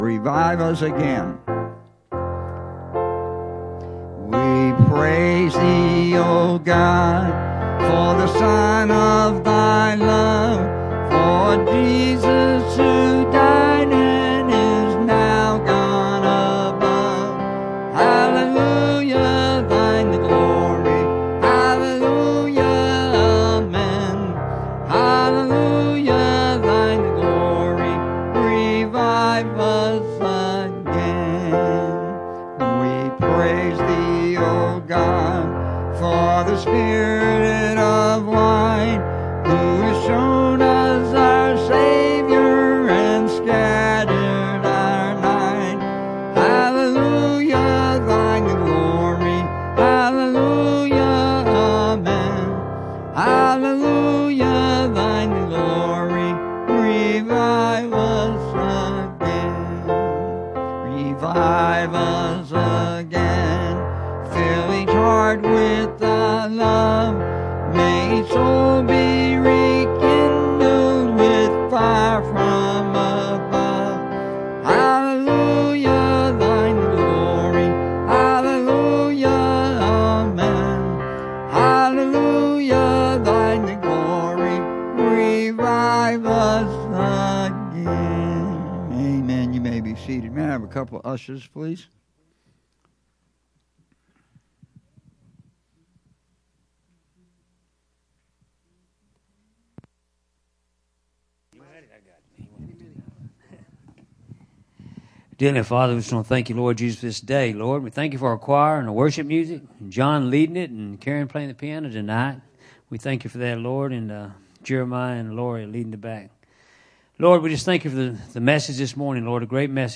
Bible Text: Revelation 6:9-11 | Preacher